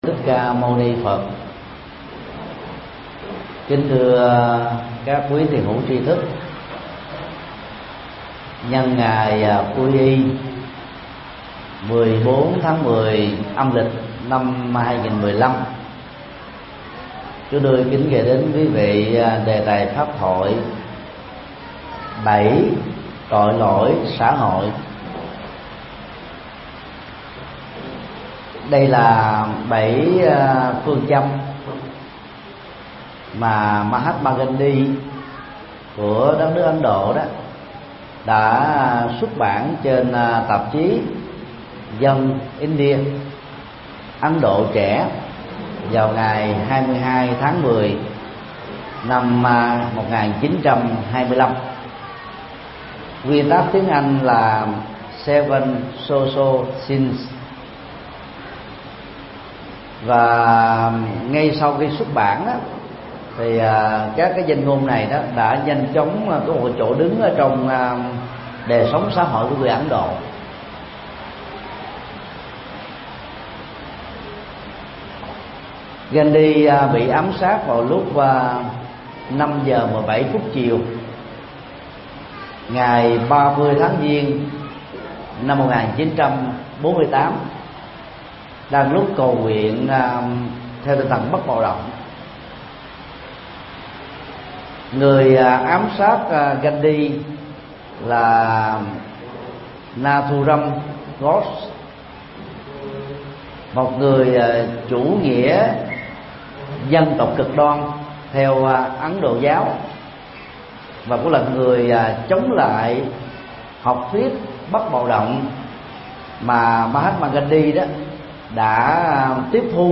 Tải mp3 Pháp Thoại Bảy tội lỗi xã hội (Bản Livestream) – Thầy Thích Nhật Từ Ngày 25 tháng 11 năm 2015